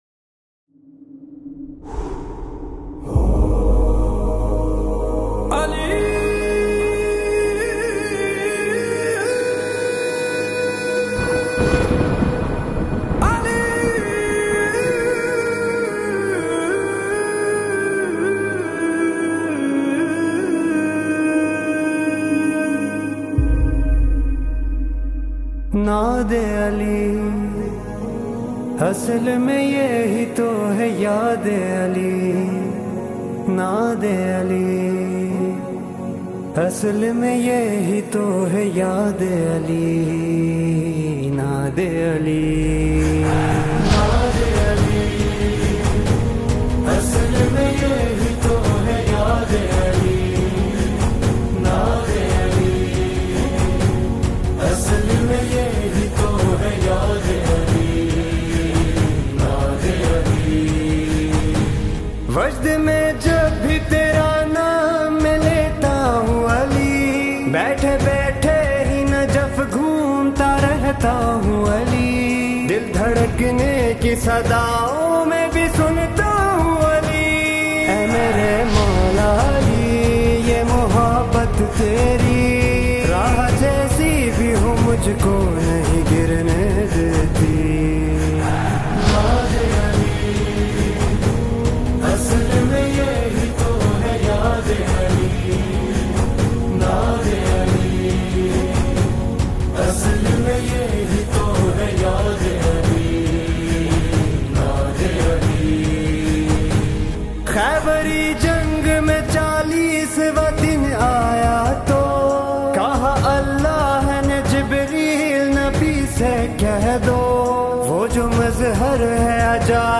دانلود نماهنگ زیبا و دلنشین